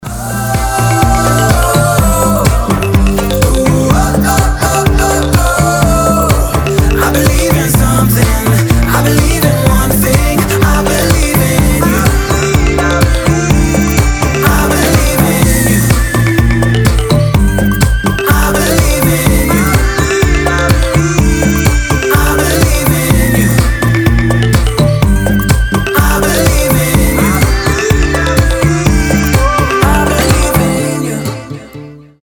• Качество: 320, Stereo
поп
позитивные
мотивирующие
dance
Dance Pop
tropical house
вдохновляющие